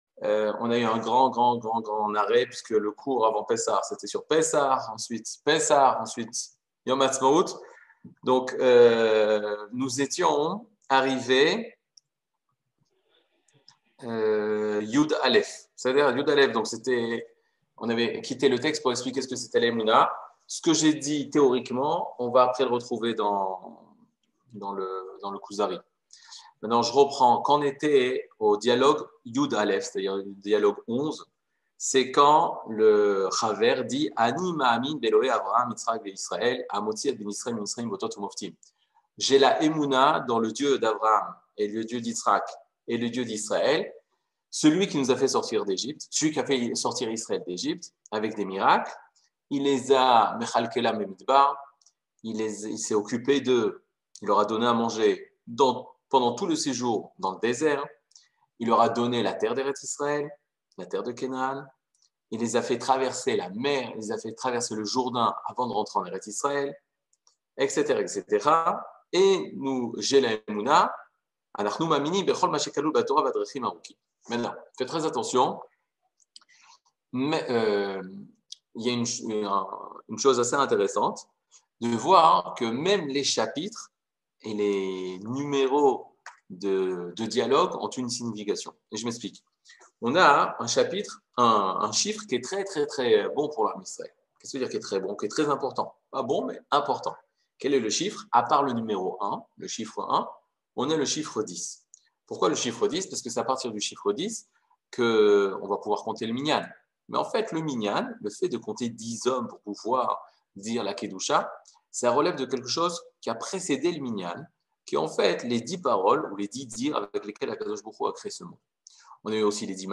Catégorie Le livre du Kuzari partie 19 00:57:44 Le livre du Kuzari partie 19 cours du 16 mai 2022 57MIN Télécharger AUDIO MP3 (52.85 Mo) Télécharger VIDEO MP4 (153.25 Mo) TAGS : Mini-cours Voir aussi ?